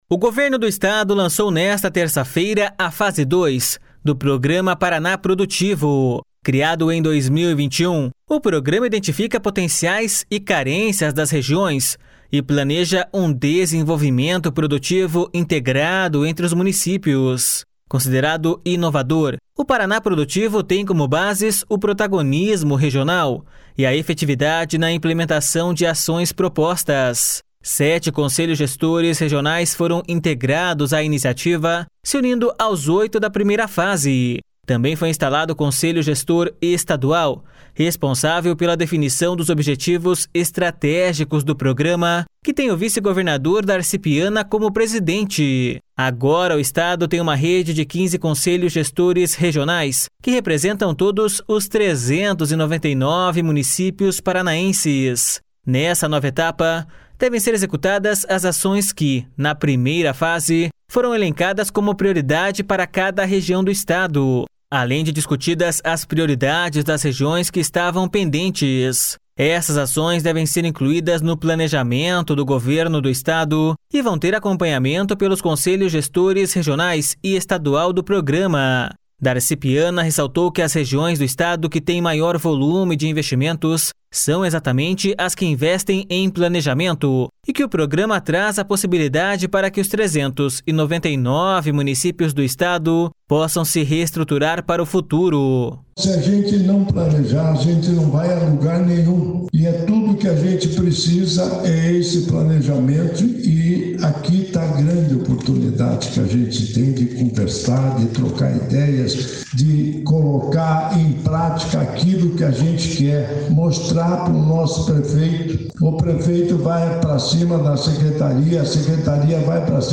O secretário do Planejamento, Guto Silva, explicou que o Paraná Produtivo tem sido instrumento importante de governança, dando voz à sociedade civil organizada.// SONORA GUTO SILVA.//